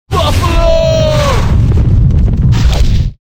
Category: Funny Ringtones